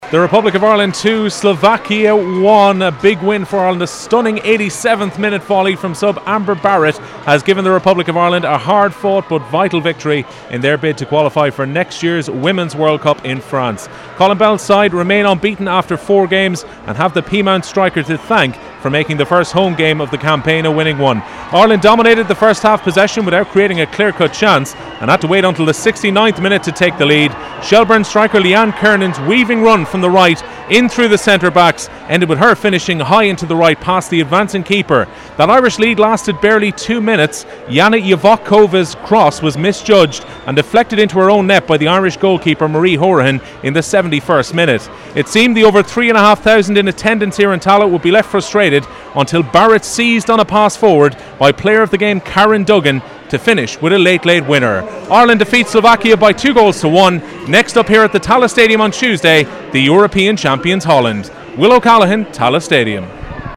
Amber Barrett fires Ireland to victory: FT Report